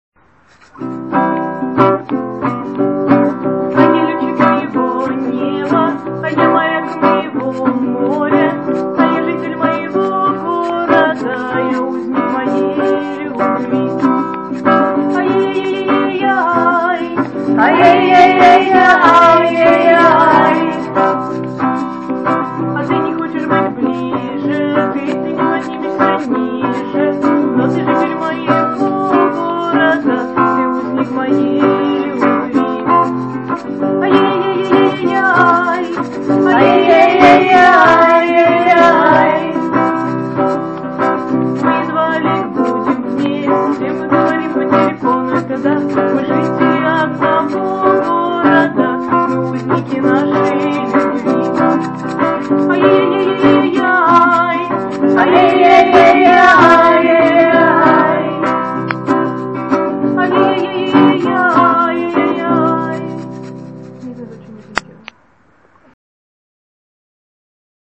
хор психованных девочек